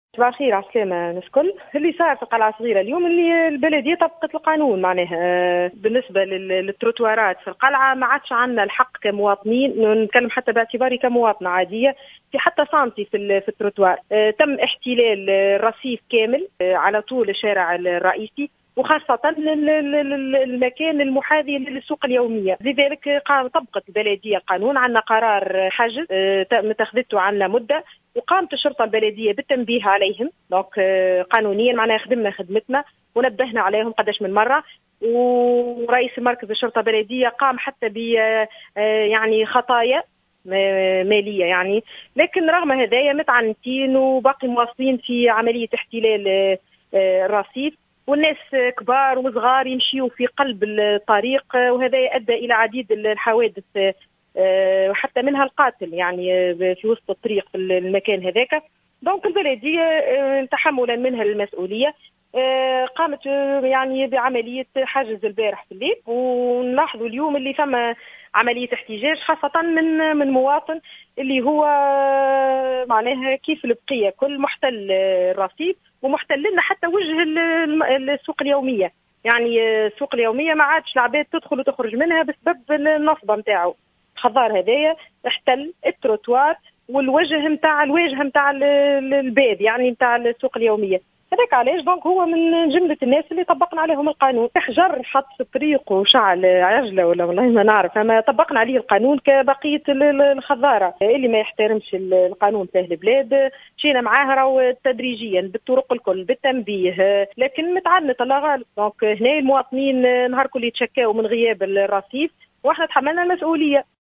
وفي تصريح للجوهرة أف أم، أكدّت رئيسة المجلس البلدي بالقلعة الصغرى سميحة بوراوي، أنه وقع تطبيق القانون وذلك بعد التدرّج في القرارات والتنبيه على المخالفين في عدّة مناسبات، وأمام تعنّت عدد من التجار الذين احتلوا الرصيف، قرّرت البلدية إنفاذ القانون، دون تردّد .